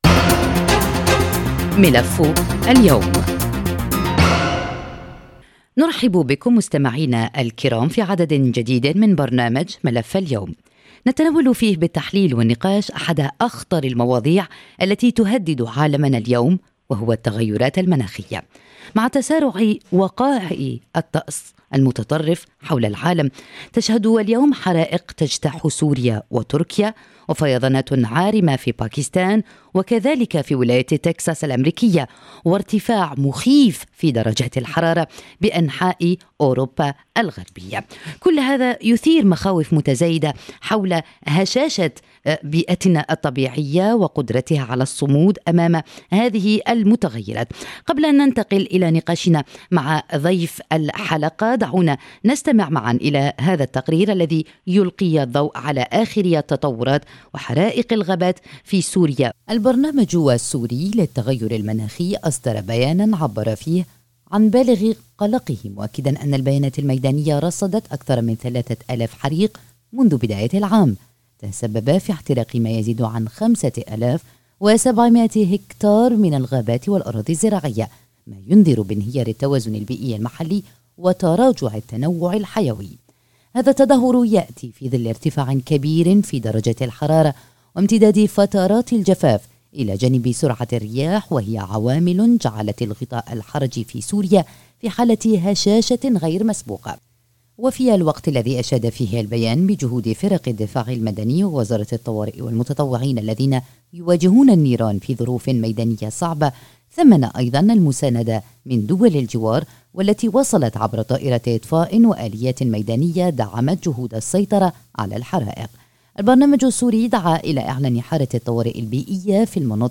في عدد جديد من «ملف اليوم»، نسلط الضوء على التغيرات المناخية التي باتت تهدد بيئاتنا ومدننا ومصادر مياهنا، ونستعرض أبرز ما يجري في سوريا وأوروبا وأميركا. ونحلل مع ضيفنا الدكتور